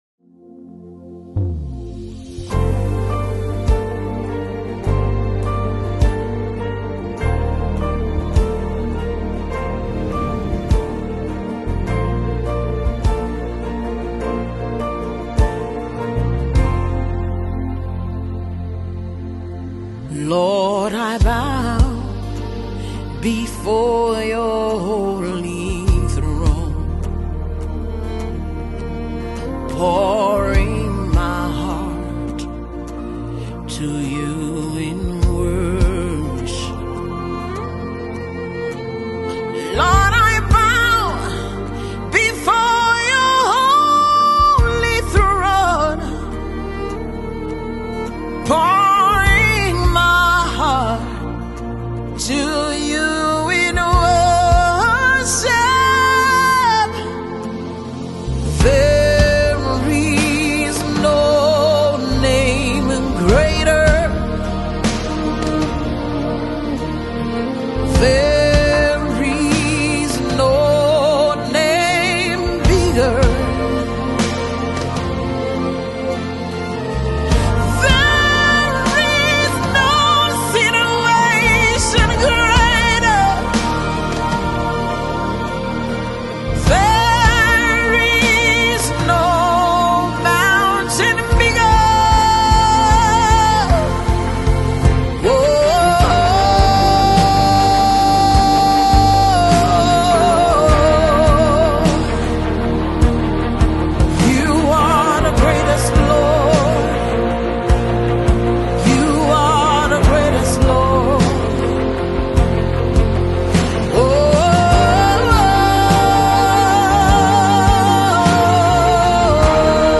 spirit lifting song